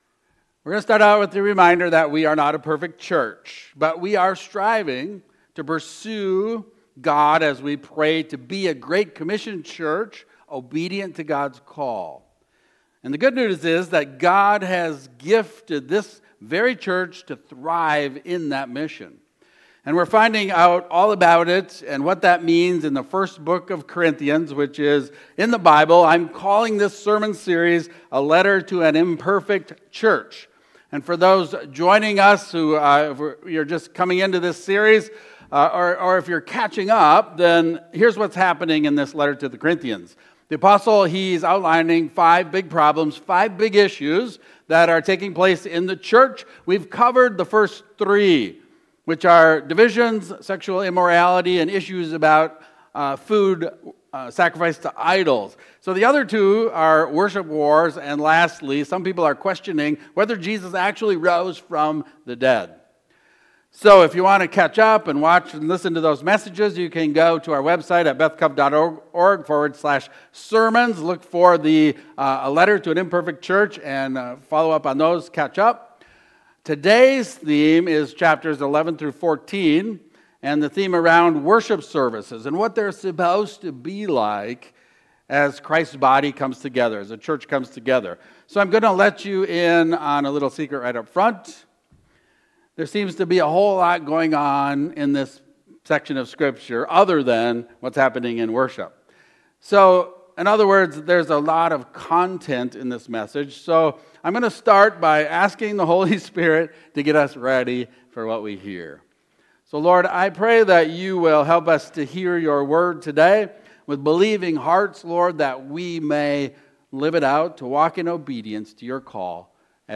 Sermons – Bethlehem Covenant Church